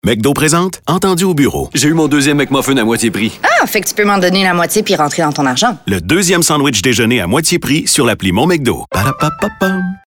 voices